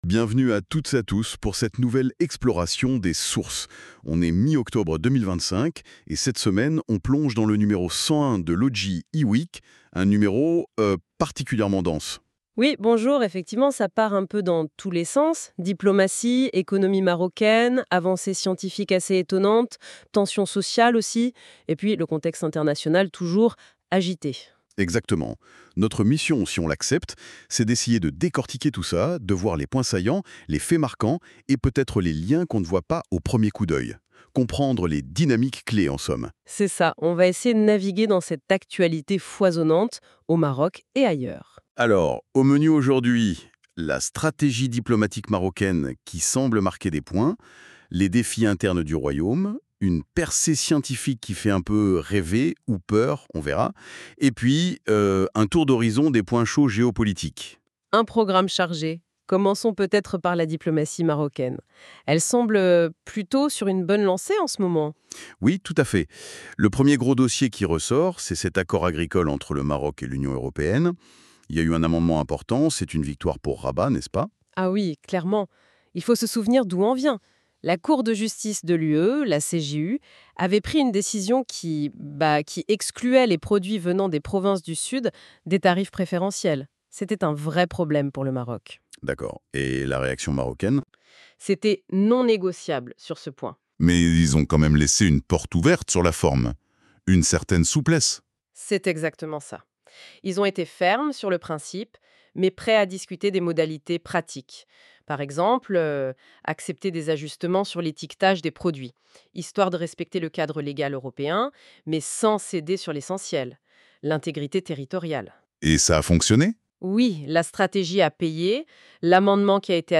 Podcast - débat IWEEK N101.mp3 (17.81 Mo)